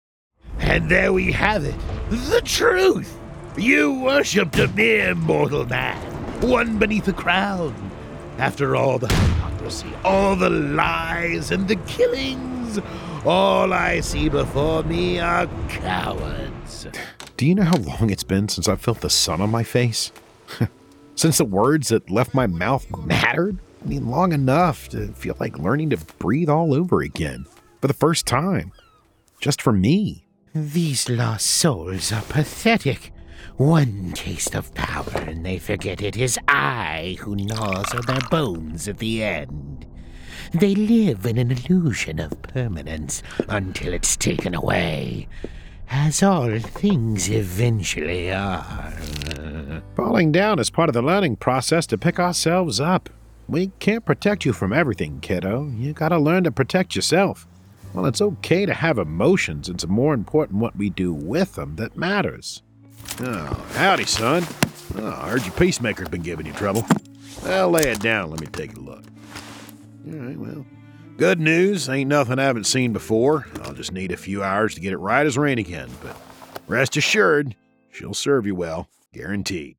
Adult (30-50) | Older Sound (50+)